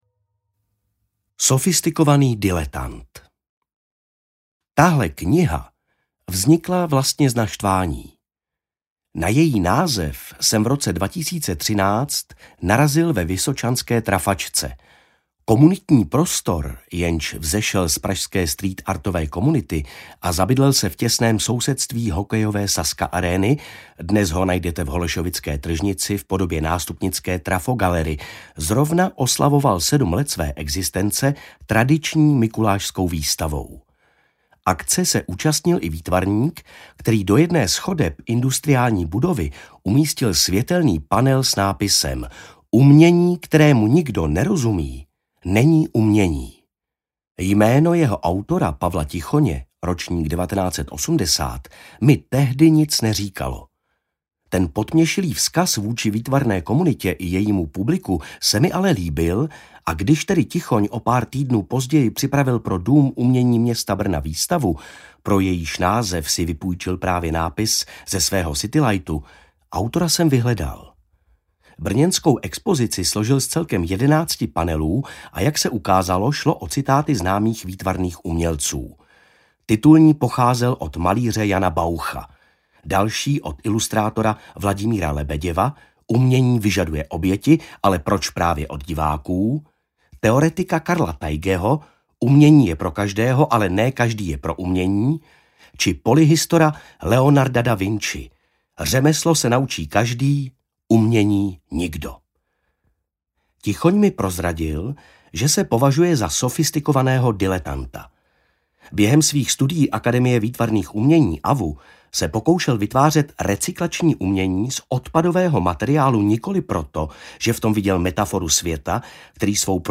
Umění, kterému nikdo nerozumí: Historky z podsvětí výtvarné kultury - Jan H. Vitvar - Audiokniha